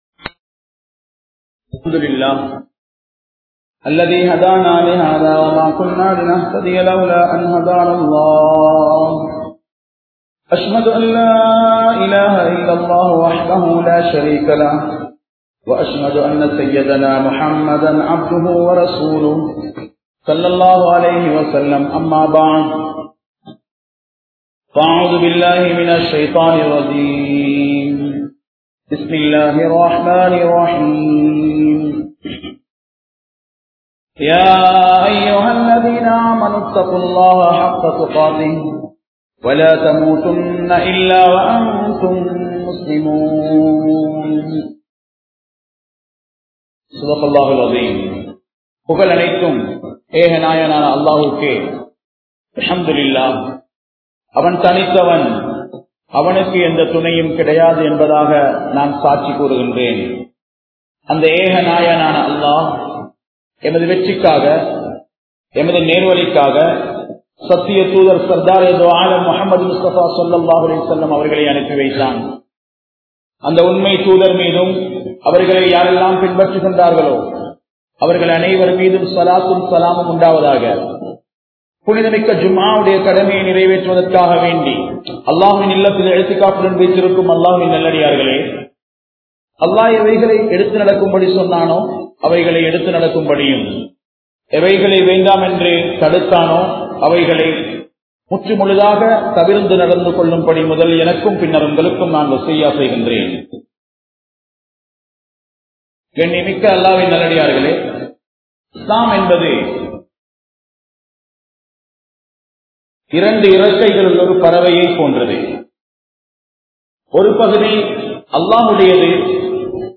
Petroarhalai Mithikkum Pillaihal (பெற்றோர்களை மிதிக்கும் பிள்ளைகள்) | Audio Bayans | All Ceylon Muslim Youth Community | Addalaichenai